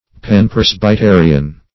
Search Result for " panpresbyterian" : The Collaborative International Dictionary of English v.0.48: Panpresbyterian \Pan`pres`by*te"ri*an\, a. [Pan- + Presbyterian.]
panpresbyterian.mp3